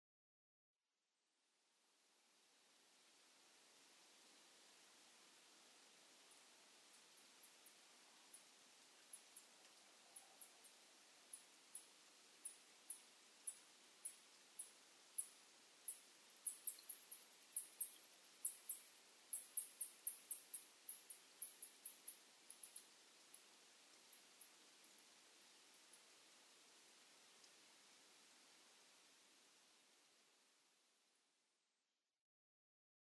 コウモリ sp.　a kind of a Bat
奥日光戦場ヶ原　alt=1400m
Mic: built-in Mic.